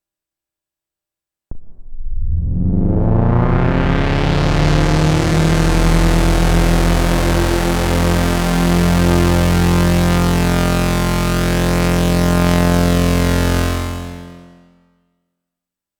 THX-like sweep from the OB-6 alone. The OB doesn’t have enough modulation to get the complexity of the real thing, but it still sounds pretty cool.
Uhhhh so PHAT thank you haha